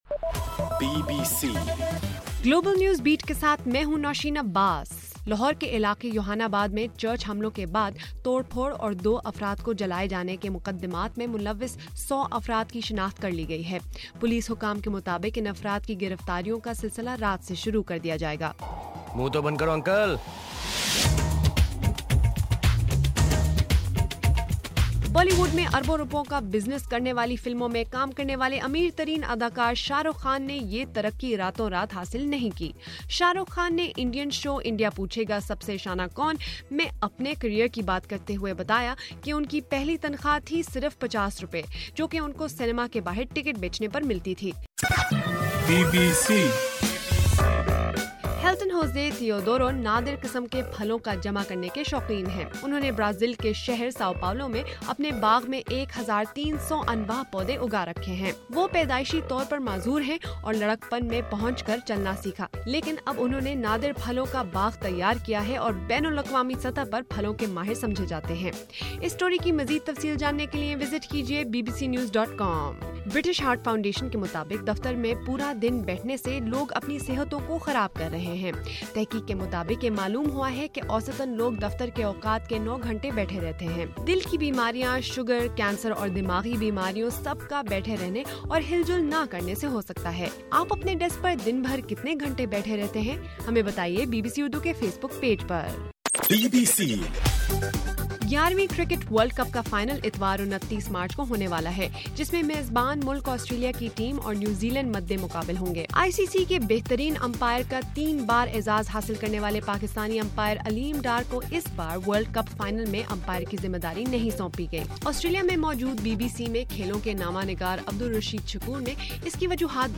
مارچ 28: صبح 1 بجے کا گلوبل نیوز بیٹ بُلیٹن